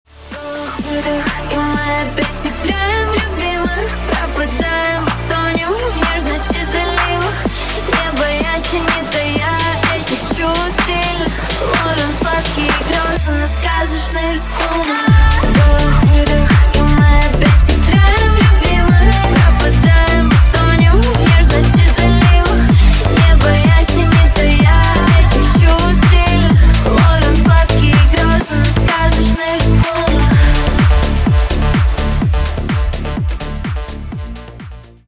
на русском про любовь клубные